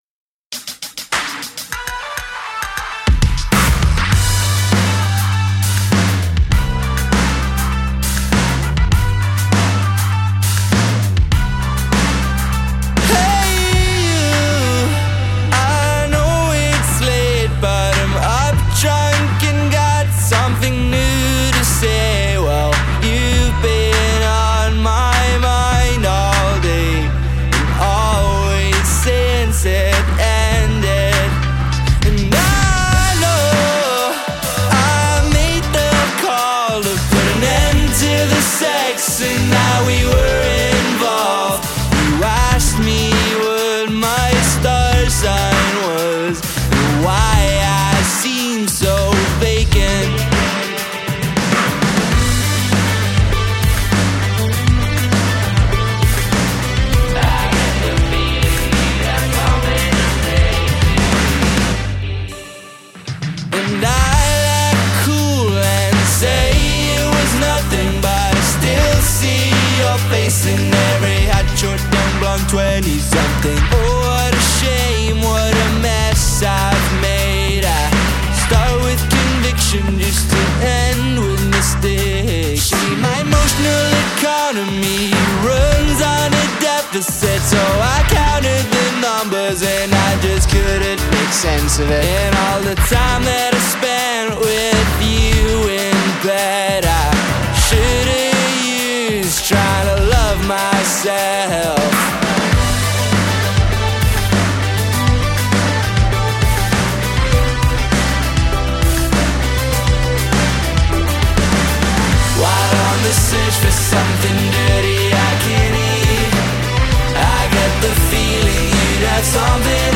indie outfit